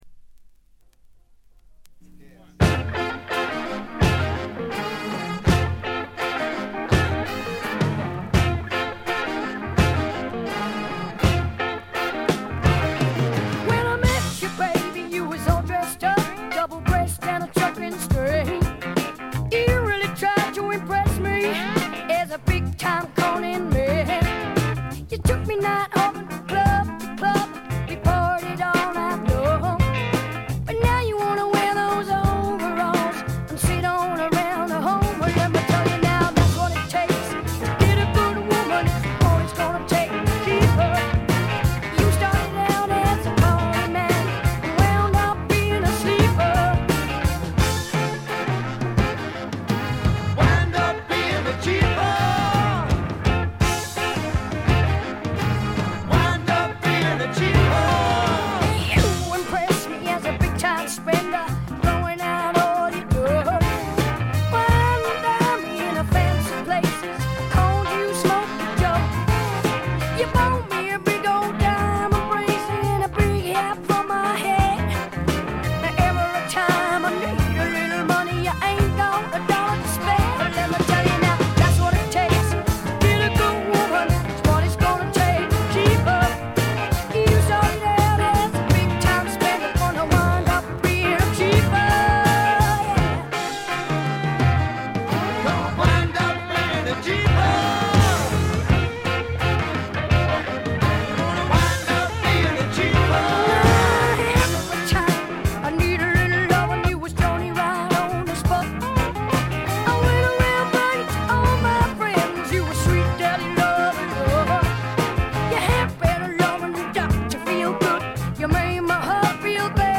ホーム > レコード：英国 スワンプ
録音はロンドンとナッシュビルで録り分けています。
試聴曲は現品からの取り込み音源です。